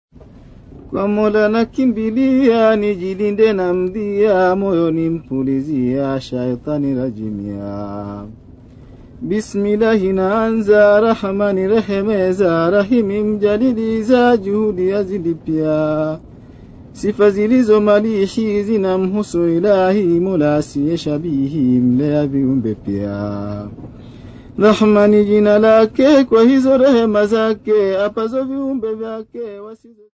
Sukuma men
Folk Music
Field recordings
Africa Tanzania city not specified f-tz
sound recording-musical
Indigenous music